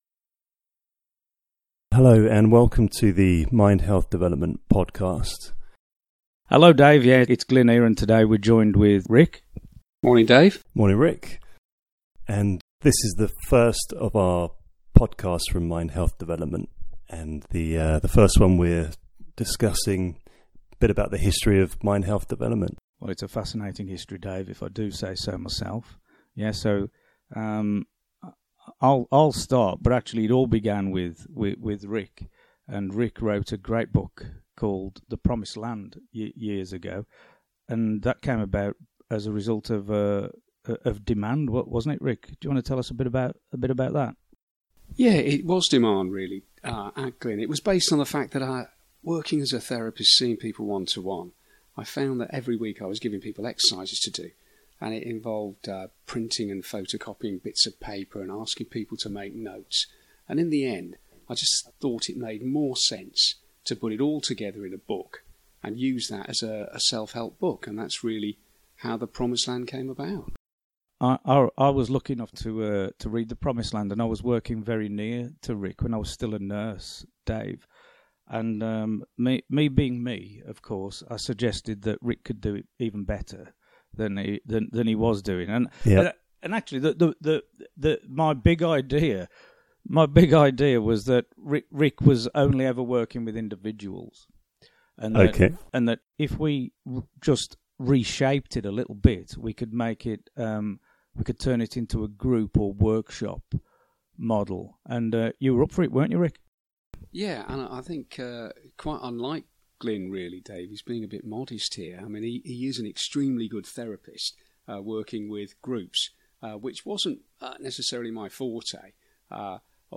Listen to the guys as they get to grips with the workings of the new MHD studio in their first podcast. Here they introduce you to the company and its aims including helping people live more fulfilled lives via healthy psychology.